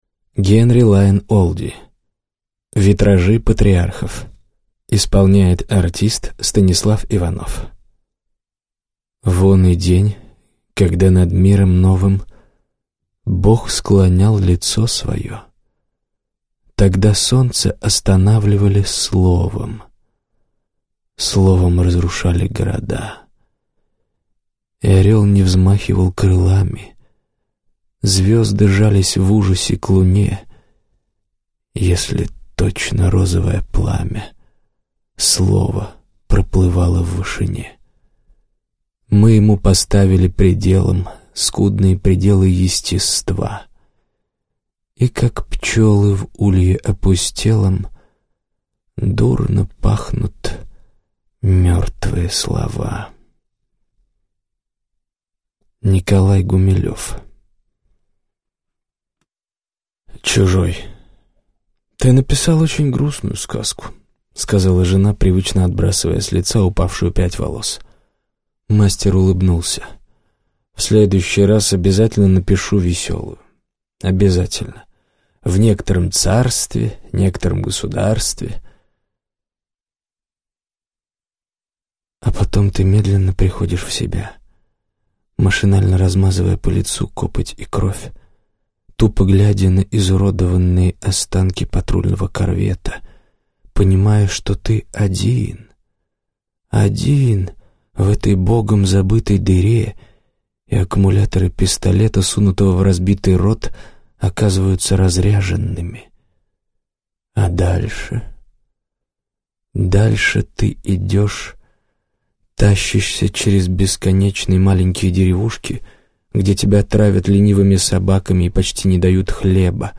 Аудиокнига Витражи патриархов | Библиотека аудиокниг
Прослушать и бесплатно скачать фрагмент аудиокниги